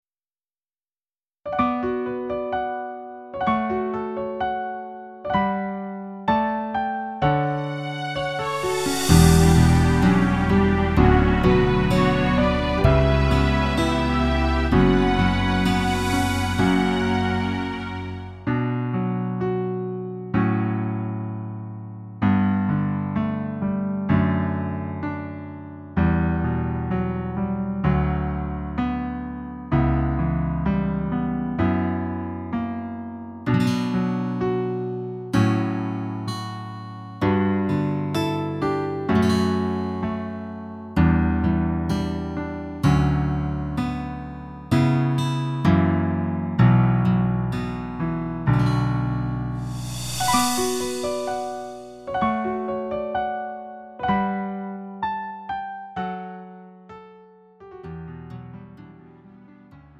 음정 원키 3:33
장르 가요 구분 Lite MR